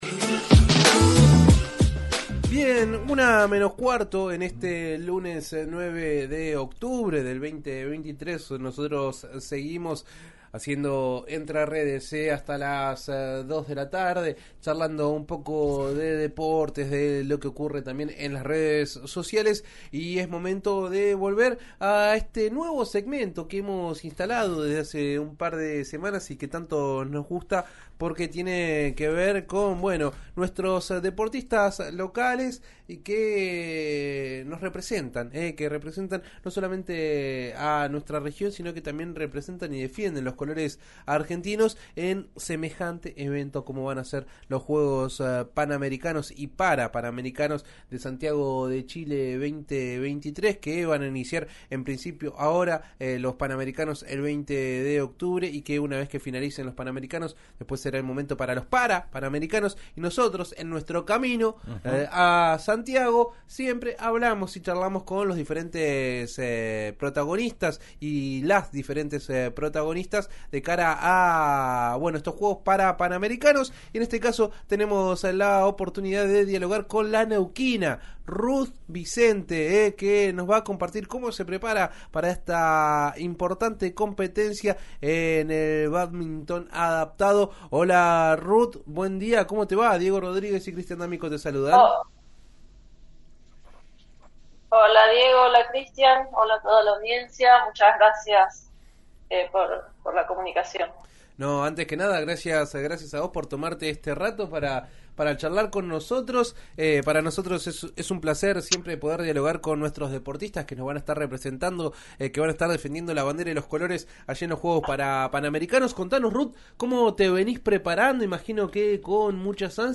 dialogó con ‘Entre Redes’ de RÍO NEGRO RADIO sobre sus expectativas de cara a Santiago 2023, sus inicios en el deporte y la importancia que tiene la competencia.